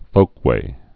(fōkwā)